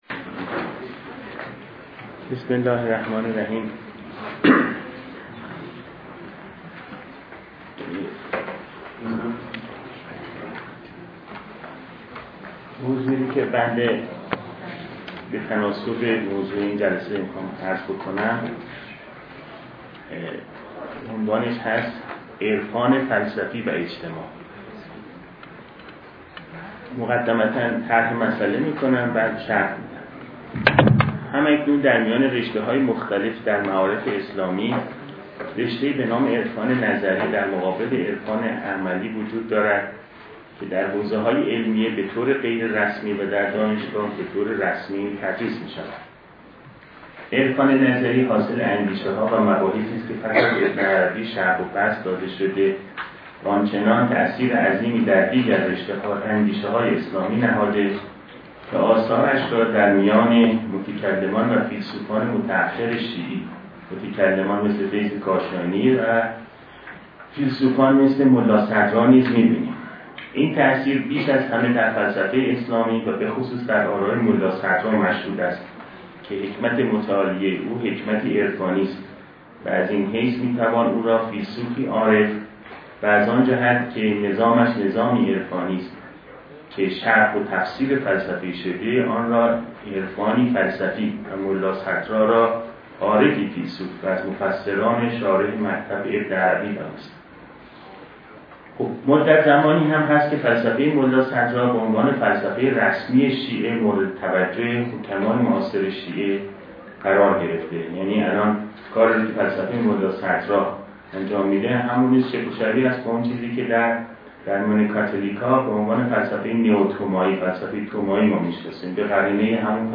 سخنرانی
در همایش روز جهانی فلسفه با موضوع «فلسفه و اجتماع» است که در آذر ماه ۹۳ به همت موسسه پژوهشی حکمت و فلسفه ایران در همین مرکز برگزار شد.